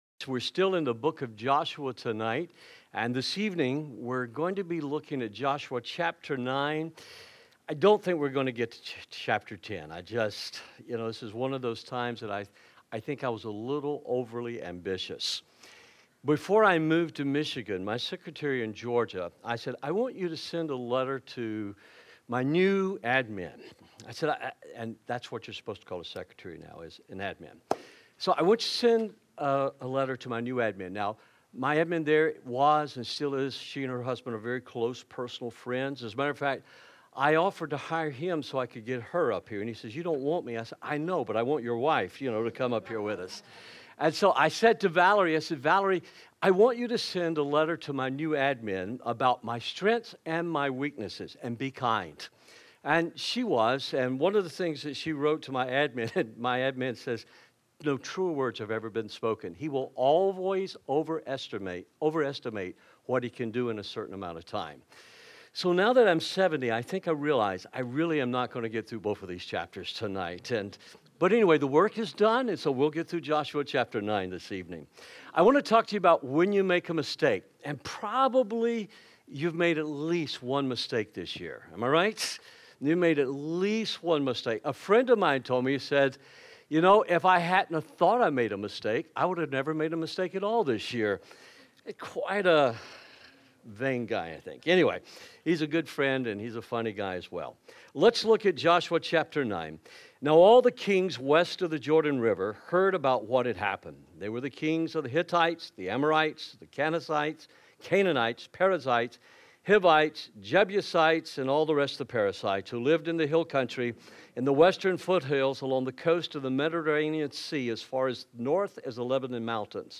Messages